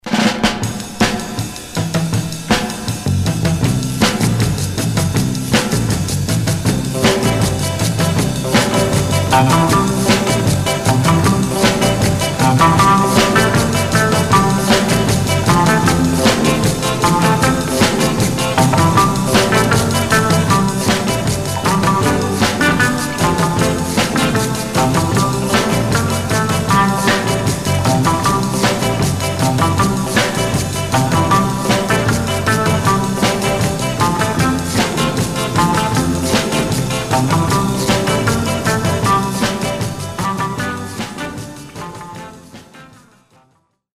Some surface noise/wear
Mono
R & R Instrumental